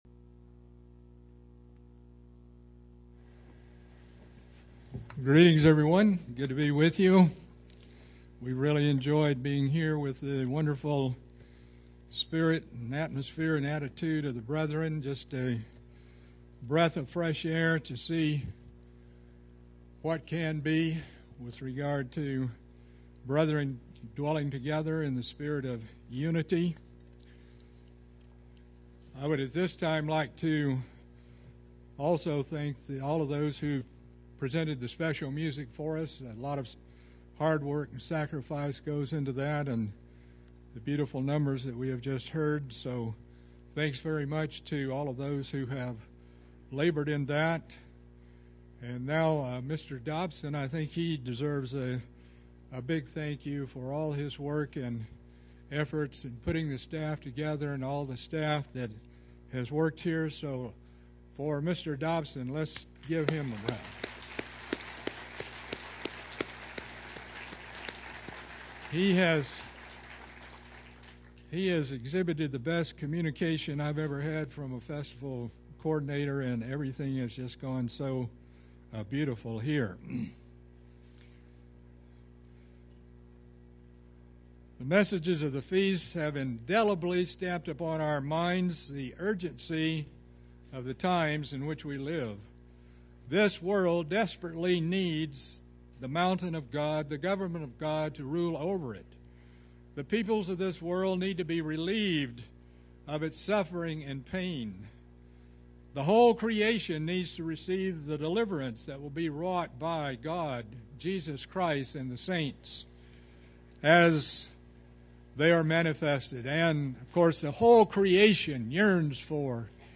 This sermon was given at the Branson, Missouri 2011 Feast site.